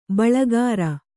♪ baḷagāra